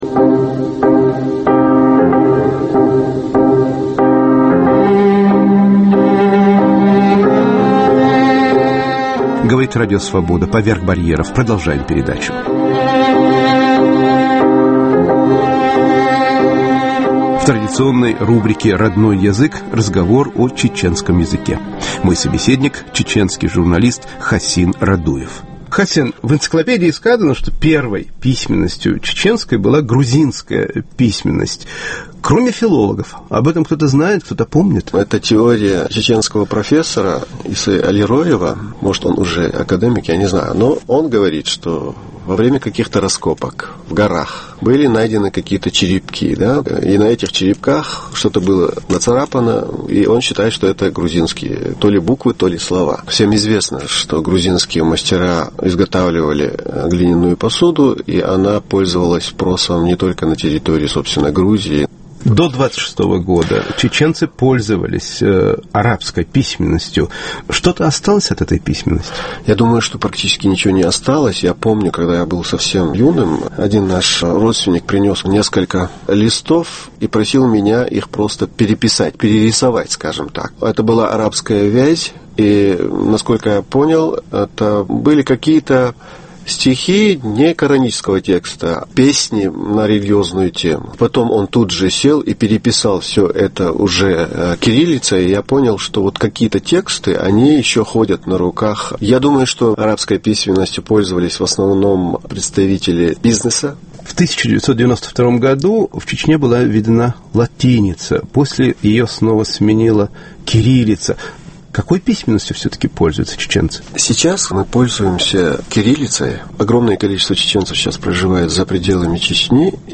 "Родной язык" - беседа о чеченском языке и о влиянии войны на язык